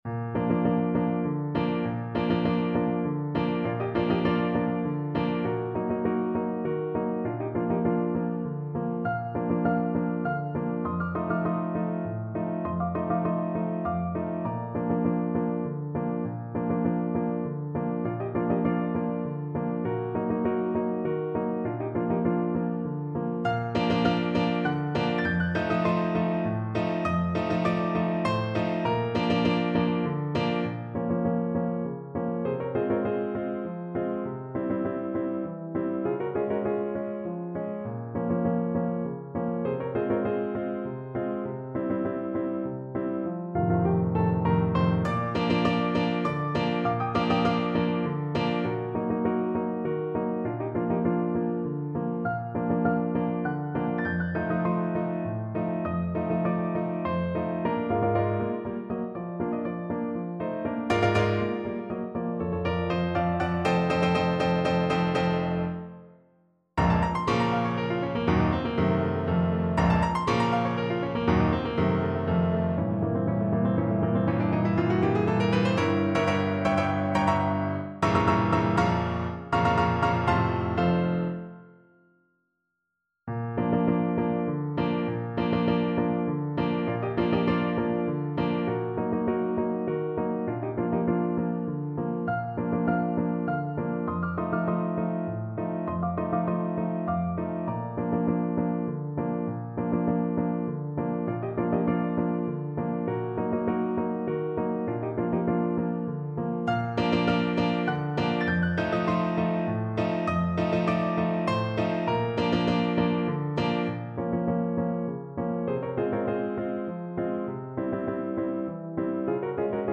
Allegro = 100 (View more music marked Allegro)
3/4 (View more 3/4 Music)
Classical (View more Classical French Horn Music)